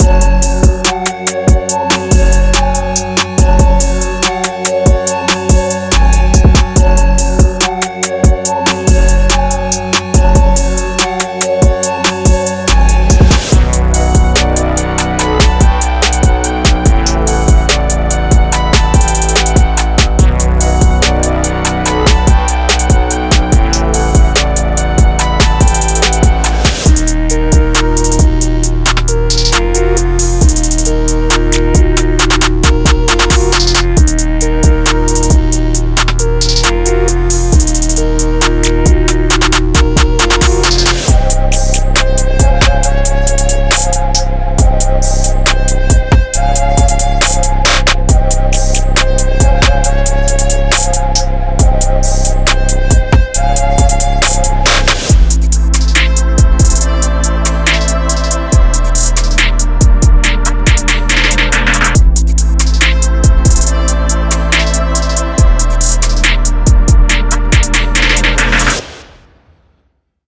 Hip-Hop / R&B Trap